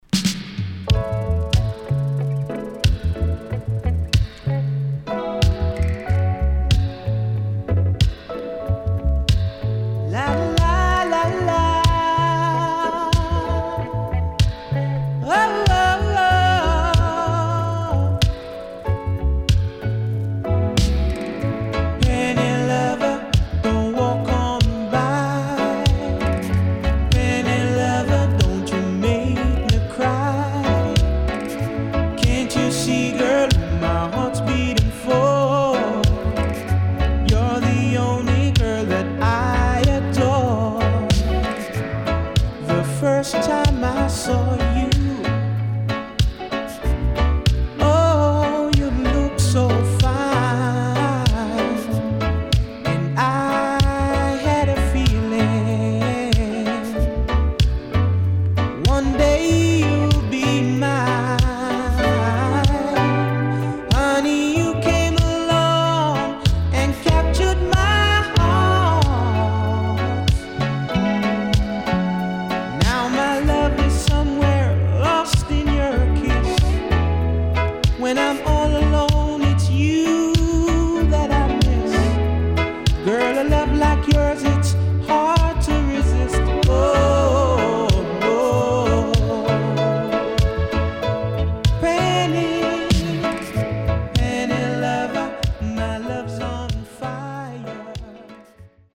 【12inch】
SIDE B:少しチリノイズ入りますが良好です。